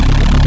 CannonLow.mp3